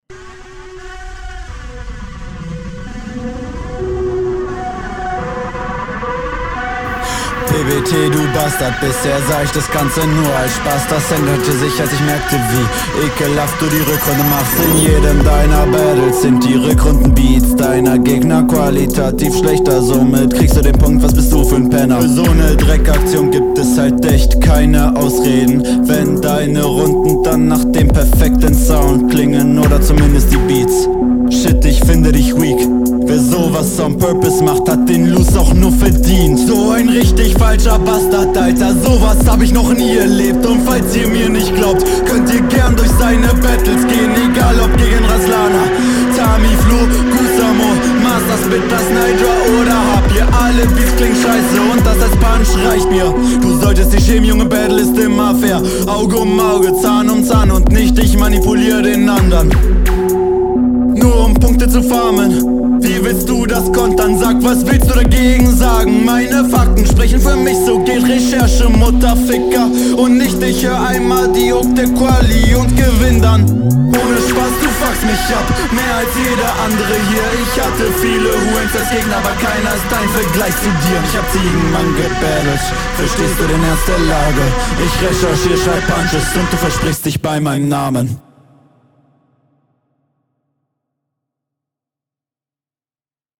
Hier passt die Distortion auf der Stimme schon besser.